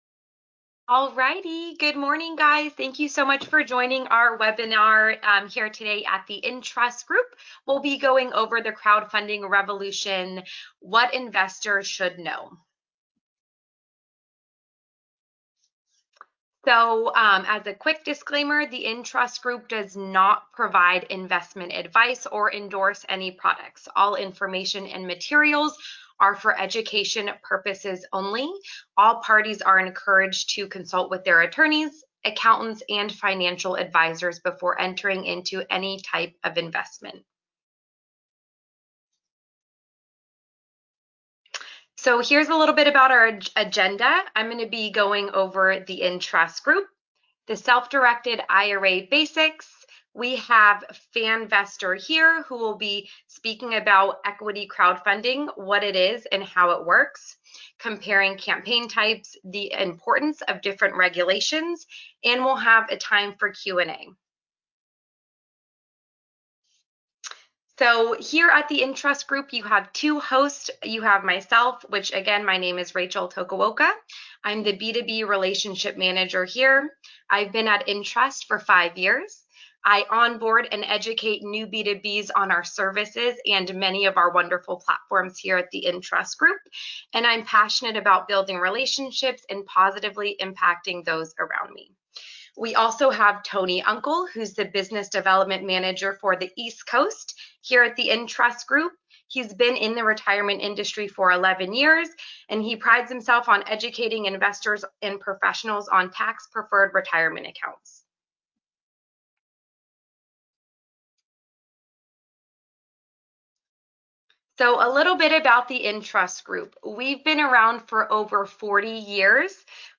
In this webinar replay, get an expert introduction to the rising trend of equity crowdfunding.